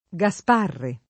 g#Sparo], Gaspero [g#Spero]; tuttora usabile se riferito a personaggi storici, Gasparre [